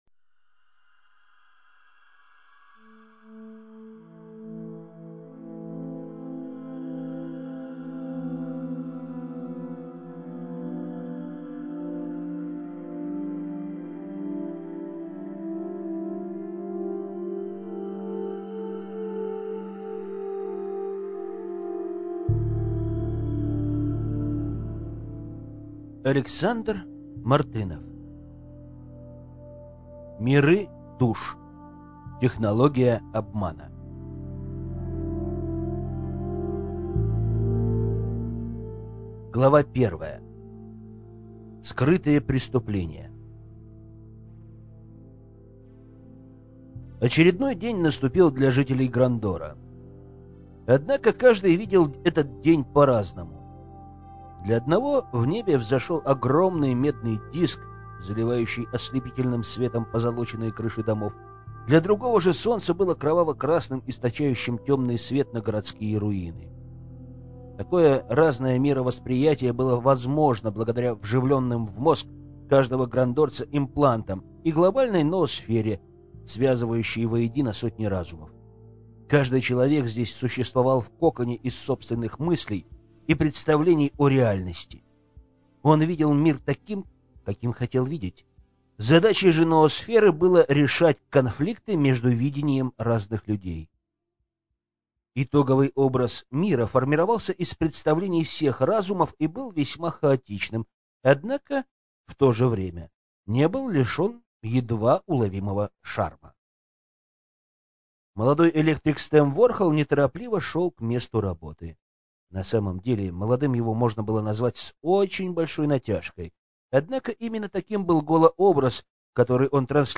Аудиокнига Миры душ. Технология обмана | Библиотека аудиокниг